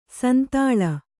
♪ santāḷa